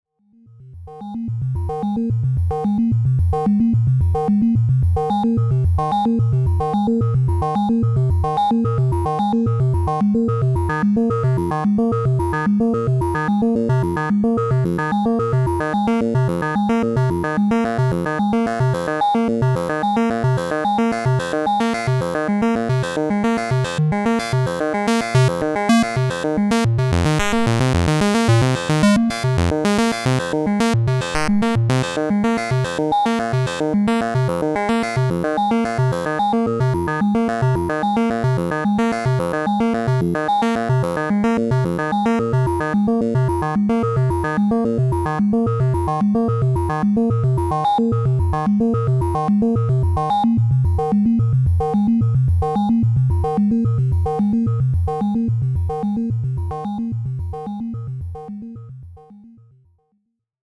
I’ve been playing with chains of single cycle waveforms on the OT today, specifically the wavetables from the PPG Wave, sliced into 64 slices. Although it’s not possible to morph smoothly between the waves, it’s still fun to switch between them using the LFOs, parameter locks, and the crossfader. Here’s a simple demo: